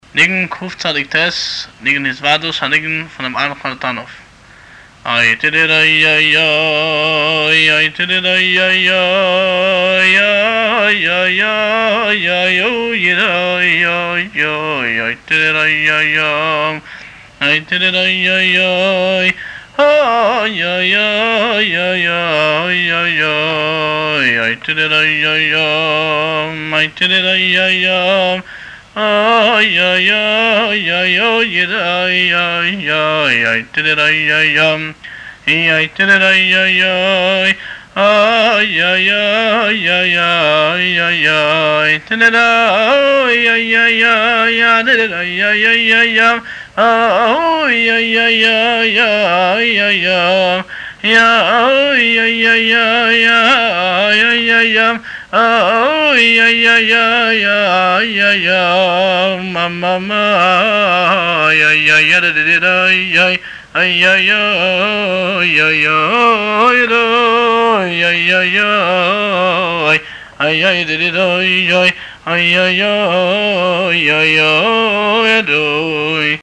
הניגון
הבעל-מנגן